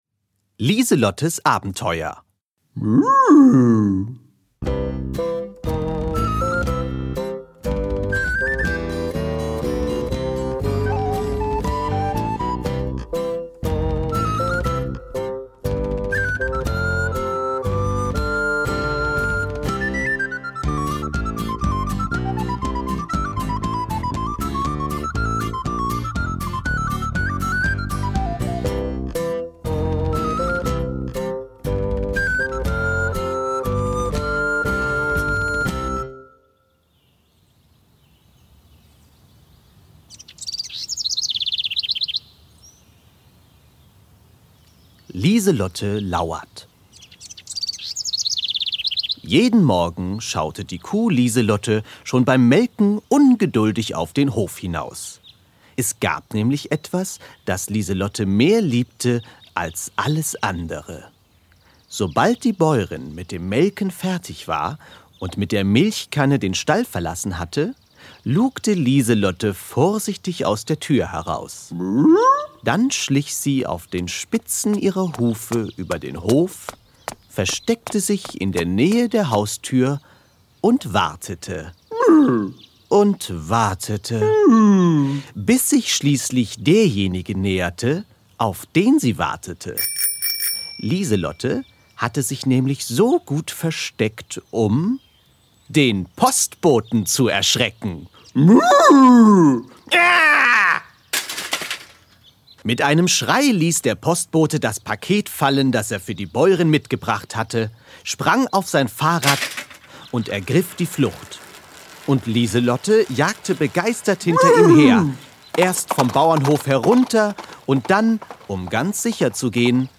Ein Kuh-Hörbuch mit viel Musik und lustigen Geräuschen für die ganze Familie!